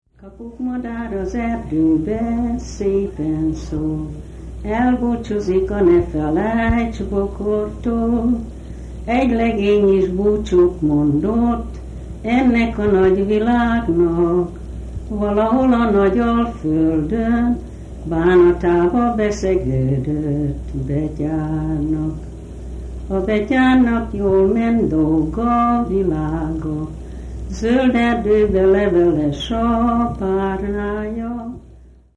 Hungarian Folk Music in the United States